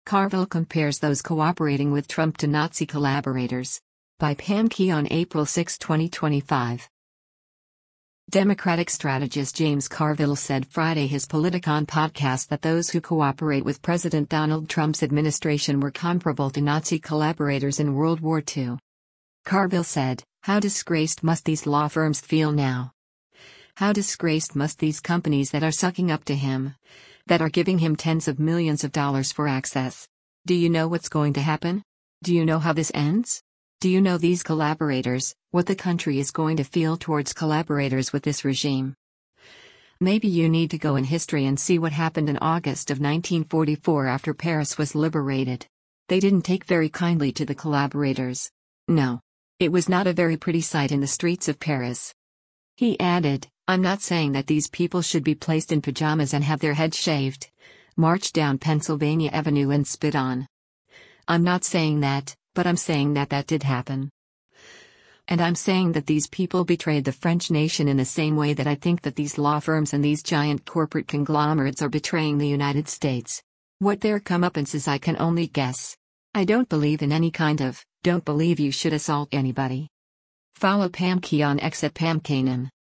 Democratic strategist James Carville said Friday his “Politicon” podcast that those who cooperate with President Donald Trump’s administration were comparable to Nazi collaborators in World War II.